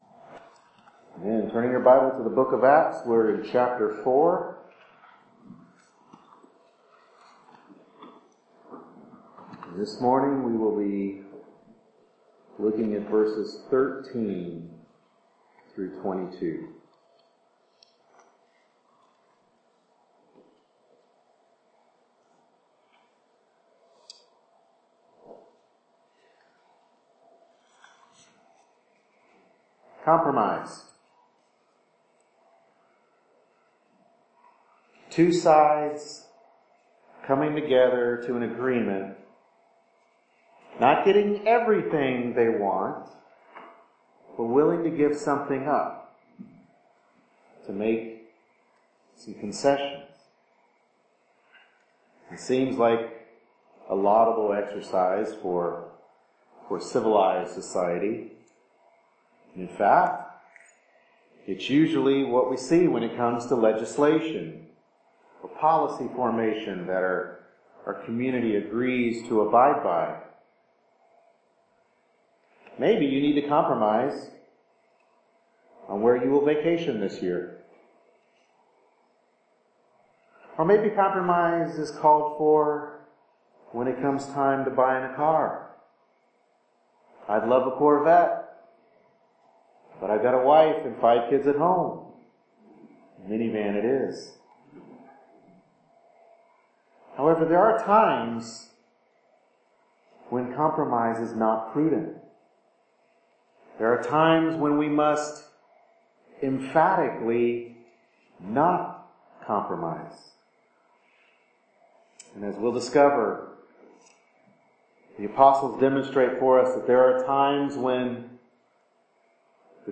Acts 4:13-22 Service Type: Morning Worship Service Bible Text